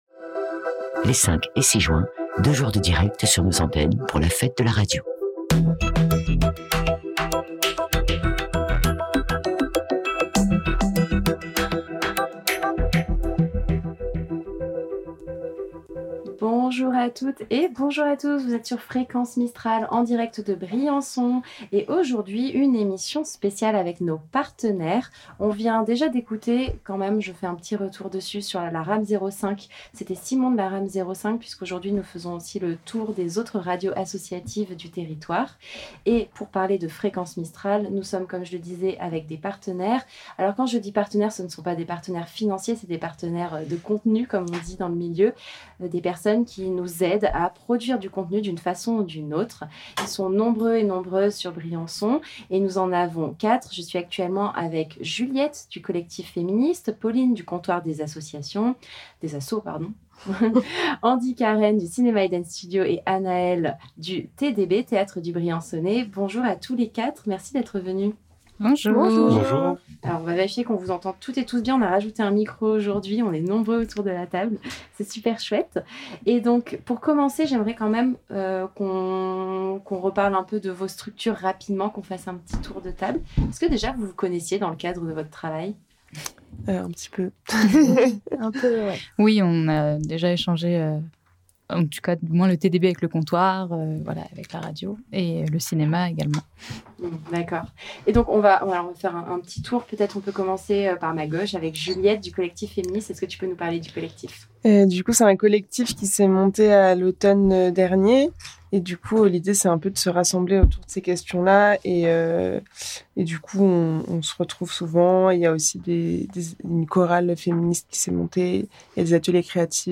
4 d'entre eux prennent le micro aujourd'hui : Collectif Féministe du briançonnais ; Comptoir des Assos ; Cinéma Eden Studio ; Théâtre du Briançonnais.
Elle fut l'occasion de deux journées de direct sur les antennes de Fréquence Mistral, à travers des émissions, des plateaux, des interviews, des visites des studios.